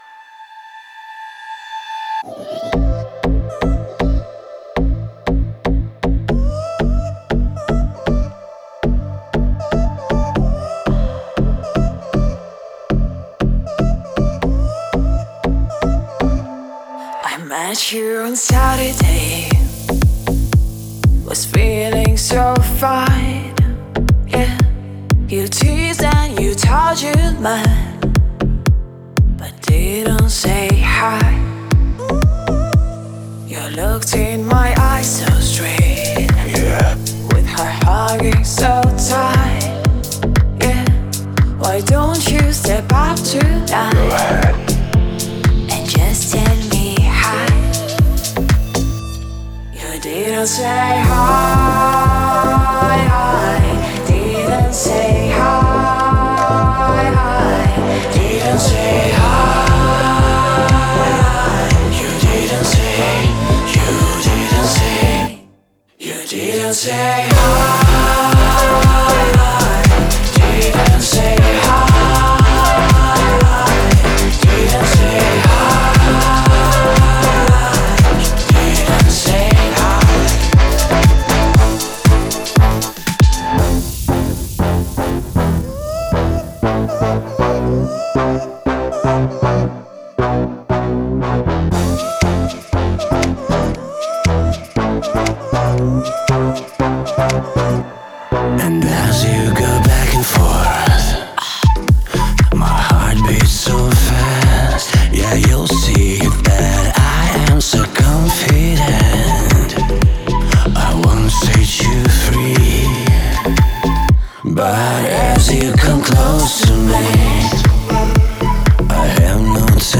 поп-песня